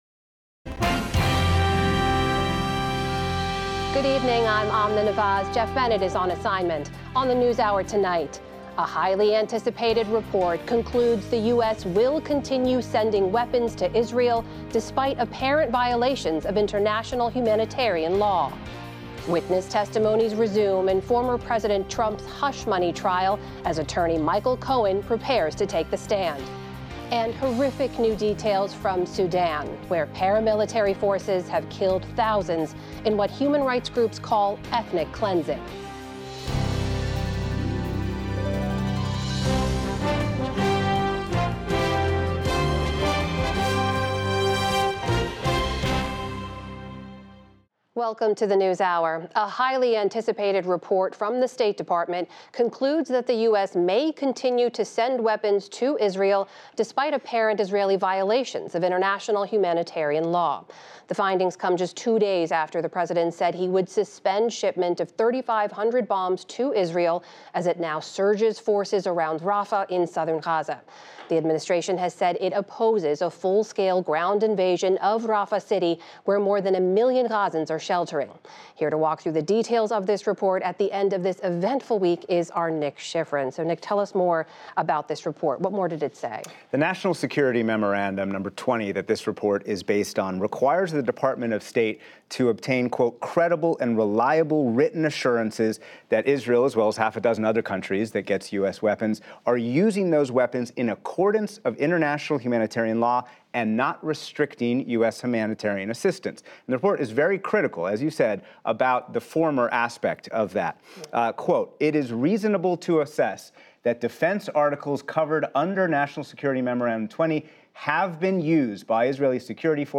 May 10, 2024 - PBS NewsHour full episode PBS News Hour - Full Show PBS NewsHour News, Daily News 4.4 • 2.1K Ratings 🗓 10 May 2024 ⏱ 53 minutes 🔗 Recording | iTunes | RSS 🧾 Download transcript Summary Friday on the NewsHour, the Biden administration says Israel is likely not adhering to international law in its war against Hamas.